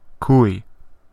Ääntäminen
IPA : /ˈðæt/ US : IPA : [ðæt]